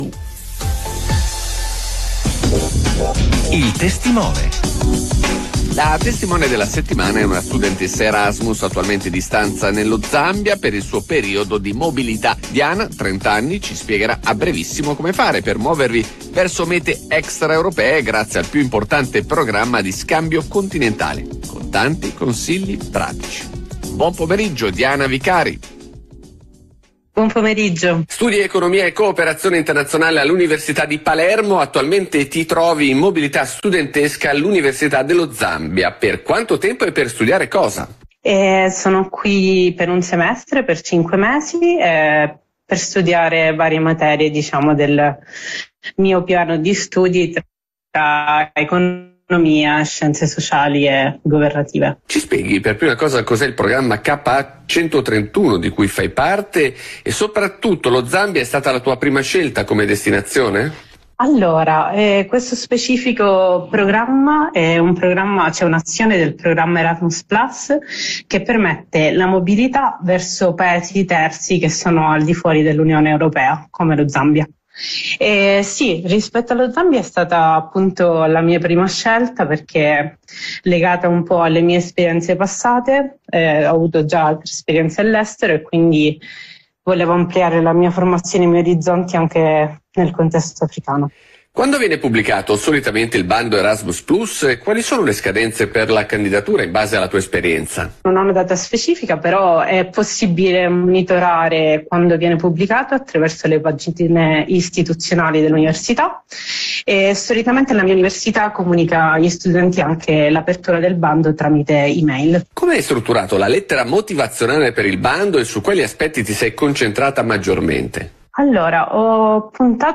contratta dell’intervista, una pillola di 6 minuti.